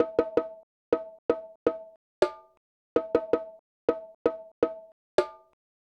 Bongos Free sound effects and audio clips
Recorded in a professional studio with a Tascam DR 40 linear PCM recorder.
• bongos and congas resample 4.wav
bongos_and_congas_sample_4_Qvu.wav